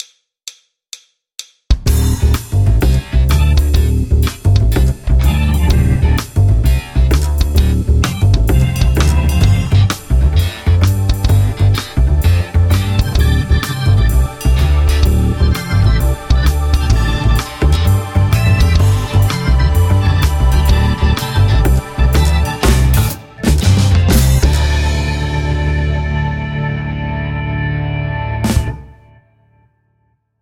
てことであまり参考にならんと思いますが、KORE PLAYERだけで作ったデモが
エフェクトは無料有料いくつか使ってます。